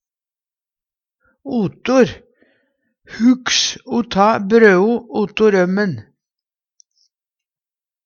otor - Numedalsmål (en-US)